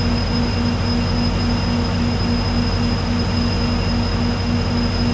diesel-loop-1.ogg